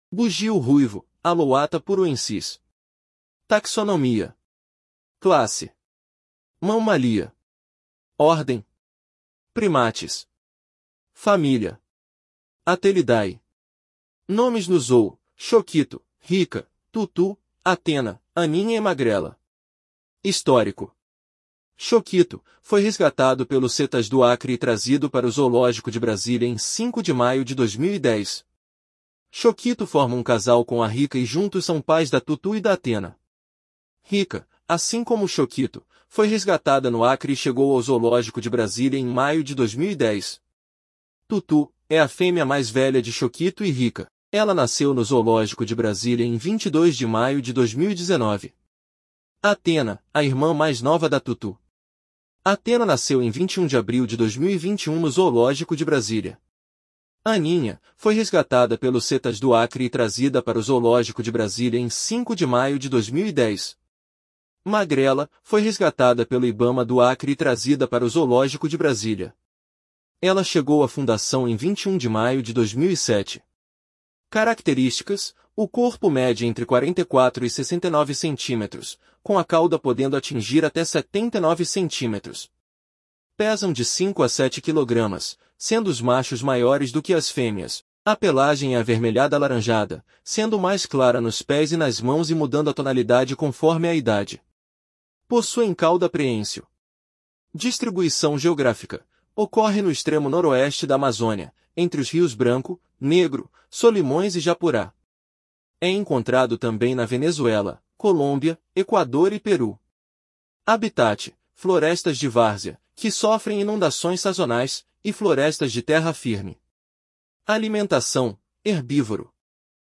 Bugio-ruivo (Alouatta puruensis)
Curiosidades: Estes primatas possuem um osso na garganta modificado que os possibilita fazer vocalizações muito altas. Estes “gritos” podem ser ouvidos a grandes distâncias, sendo usados para demarcação territorial e união do grupo. Esse comportamento acontece principalmente ao amanhecer e entardecer, sendo o coro iniciado pelo macho alfa.